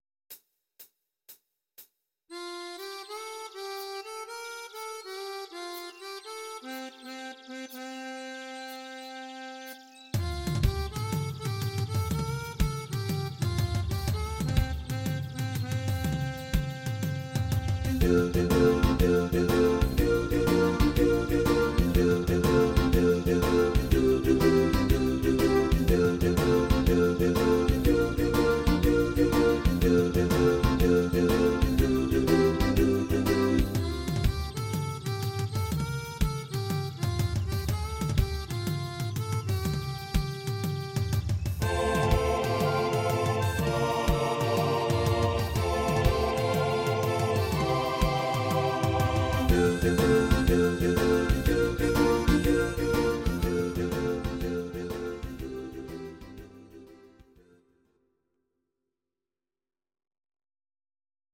Audio Recordings based on Midi-files
Pop, Medleys, 1980s